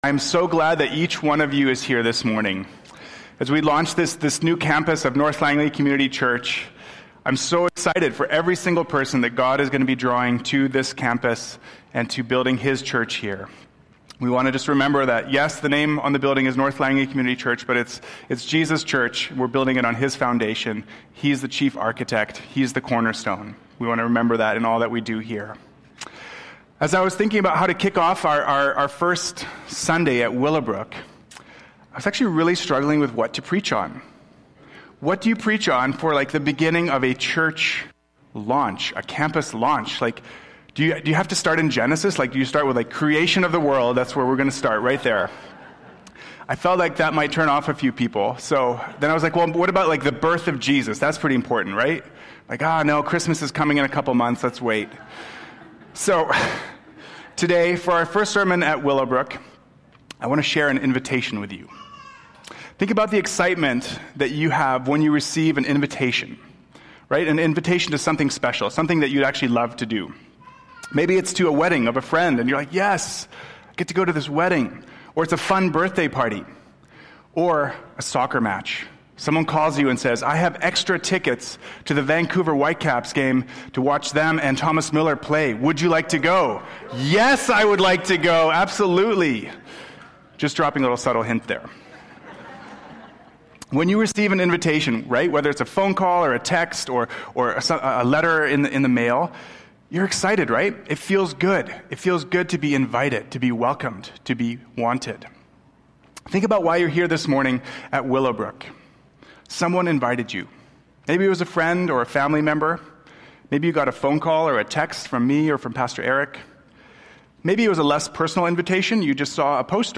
Willowbrook Sermons | North Langley Community Church